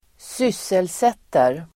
Uttal: [²s'ys:elset:er]